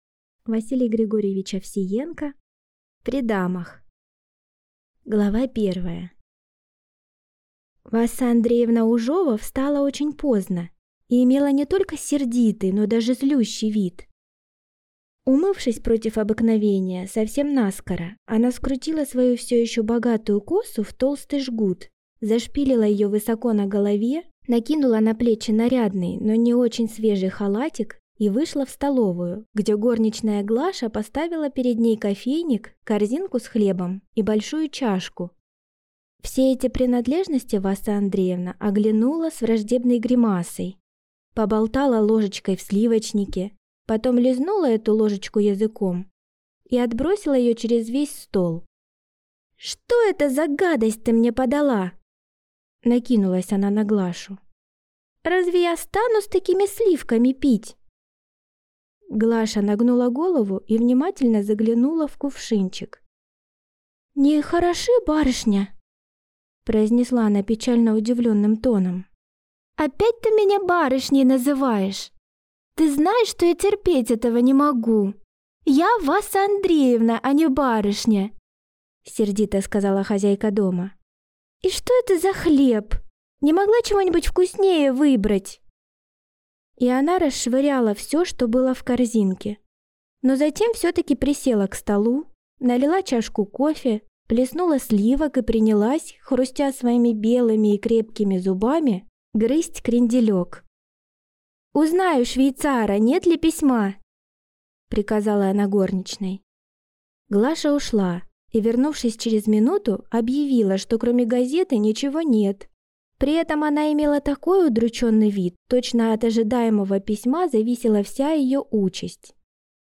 Аудиокнига При дамах | Библиотека аудиокниг